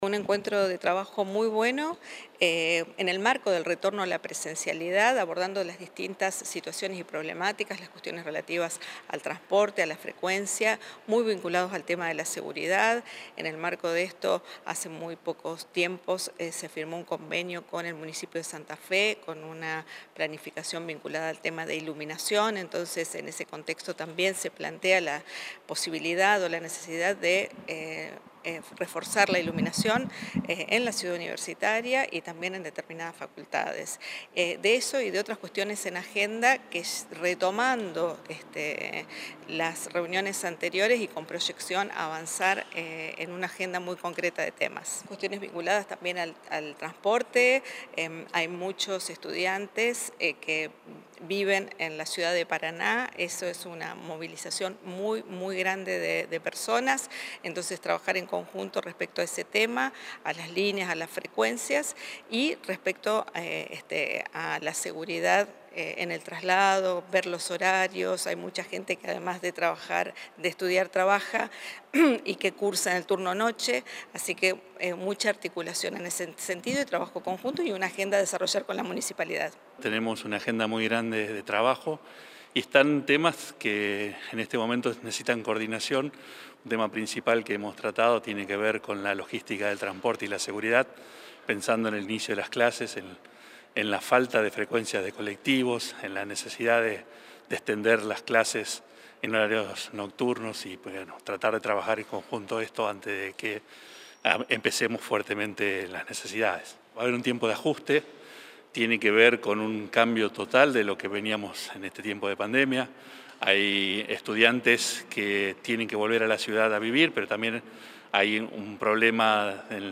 Declaraciones de Arena y Mammarella